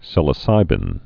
(sĭlə-sībĭn, sīlə-)